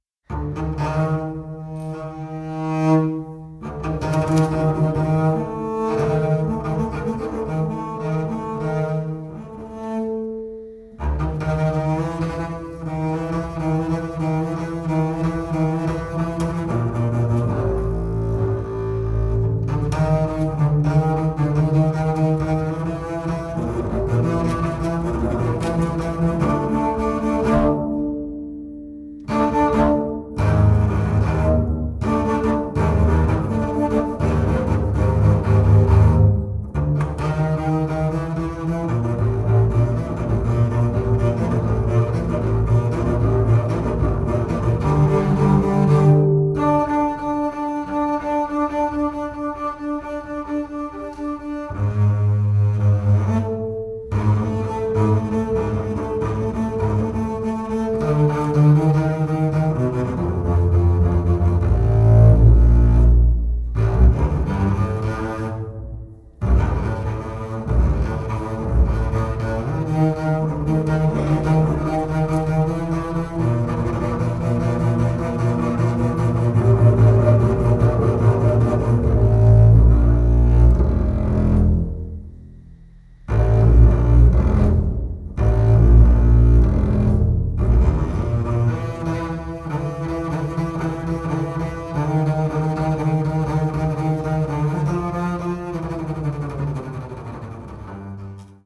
double bass, voice